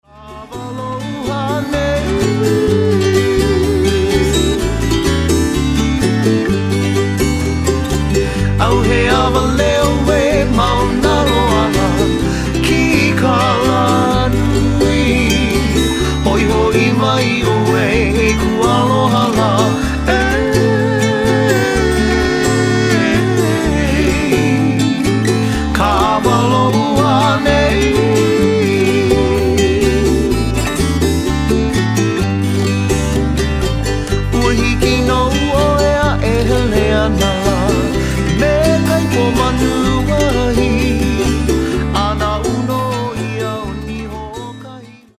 • Genre: Contemporary and traditional Hawaiian.
easy-going Hawaiian voice